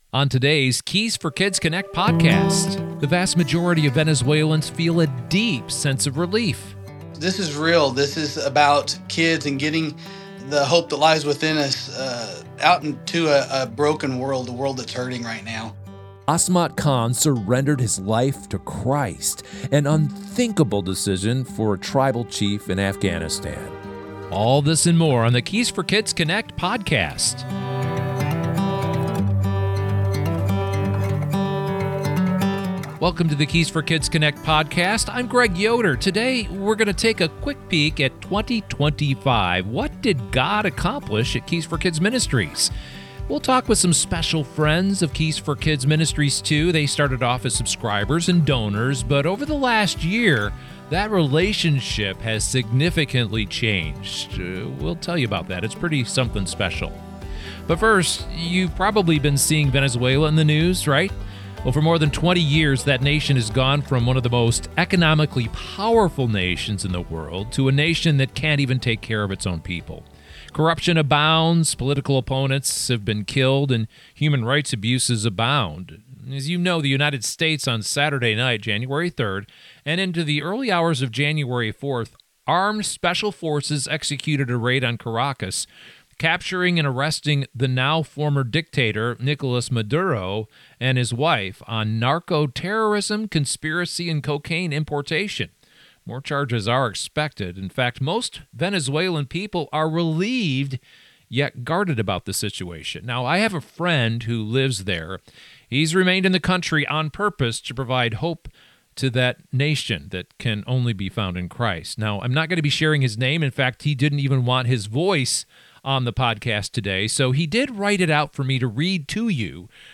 Stories from Venezuela, Home, and Afghanistan The next Keys for Kids Connect Podcast opens with a rare voice from inside Venezuela. With Nicolas Maduro suddenly removed from power, an anonymous citizen describes the relief felt by most Venezuelans after decades of economic collapse, repression, and social control. He recounts how democracy was dismantled year by year, why millions now fear losing government food boxes, and how exhaustion—not ideology—shapes the nation’s reaction.